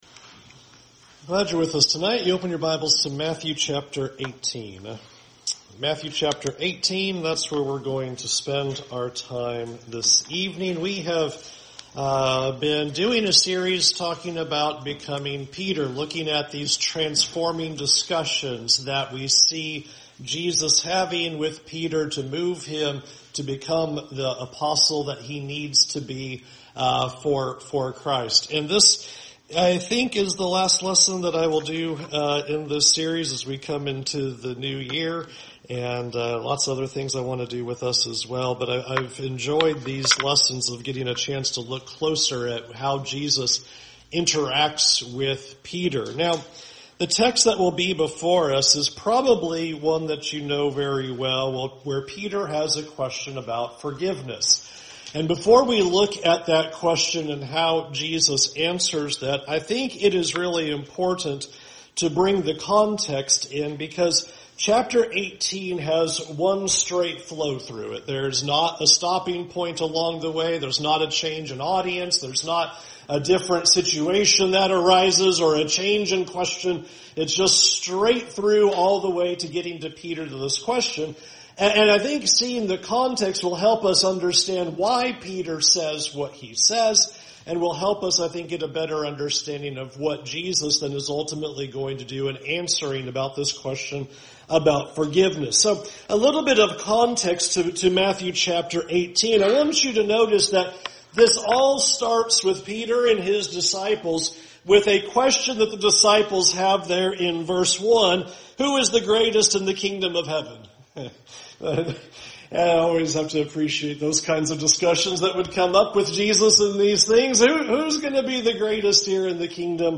We have been spending some of our evening lessons looking at how Jesus teaches and transforms Peter through the various discussions they have with each other.